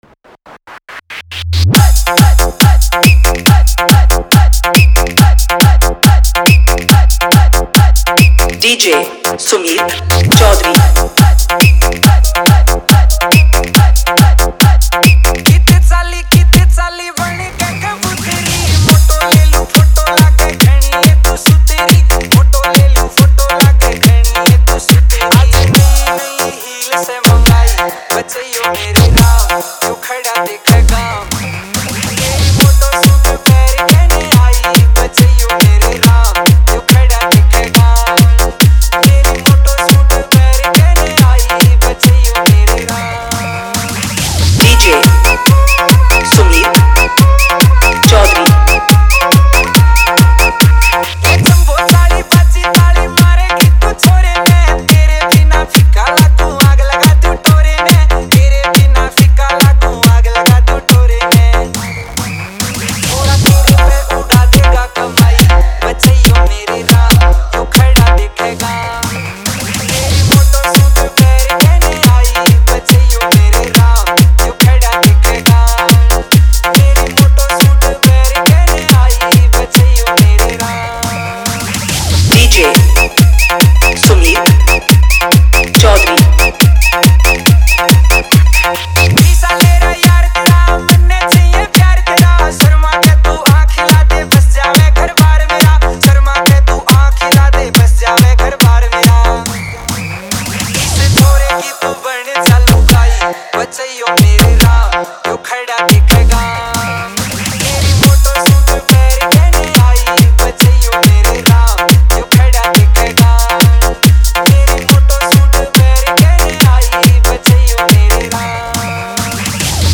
Haryanvi Remix Report This File Play Pause Vol + Vol -